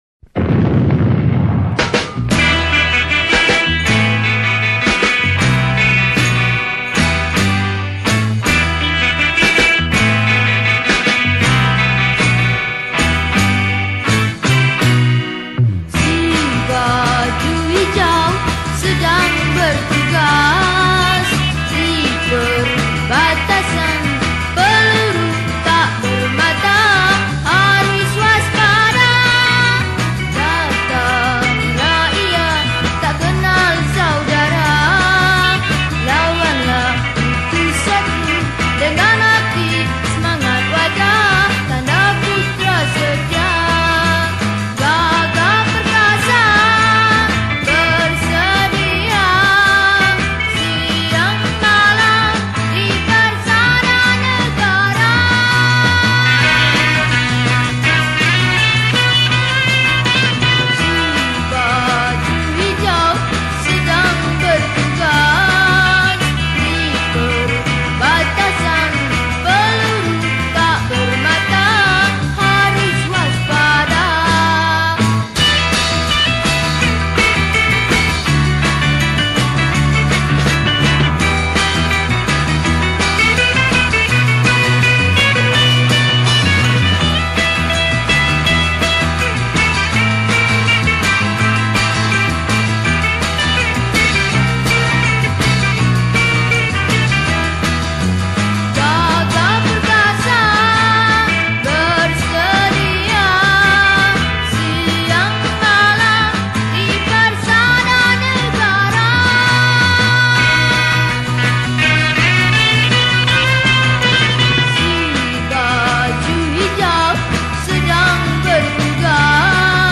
Patriotic Songs , Pop Yeh Yeh